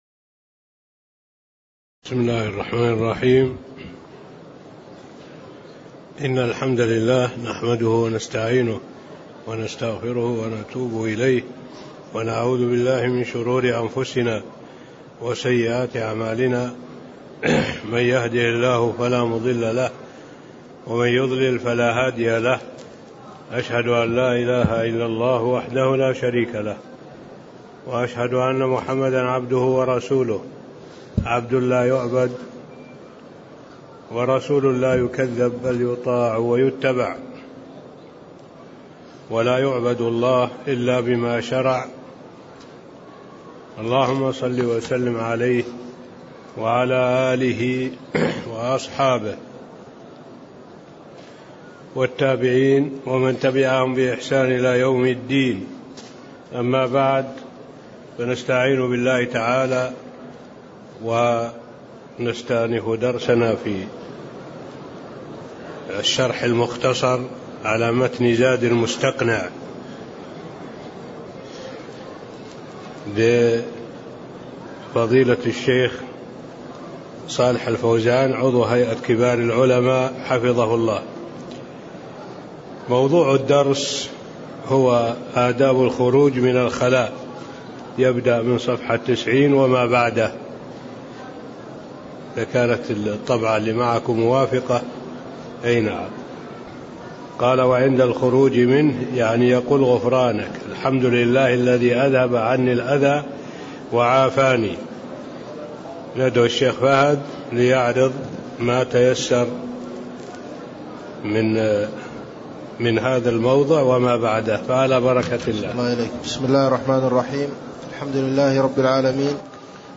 تاريخ النشر ٢٢ ربيع الأول ١٤٣٤ هـ المكان: المسجد النبوي الشيخ: معالي الشيخ الدكتور صالح بن عبد الله العبود معالي الشيخ الدكتور صالح بن عبد الله العبود آداب الخروج من الخلاء (04)